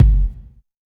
20 BOOM KK-R.wav